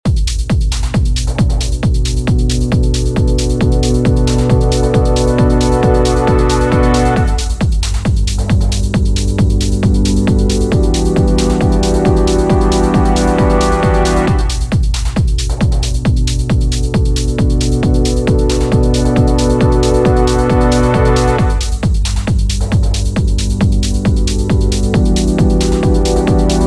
9th chords are formed when you add the note to the right of the chords’ root notes. Keep your first chord the same and add the 9th notes to the G major and E minor chords.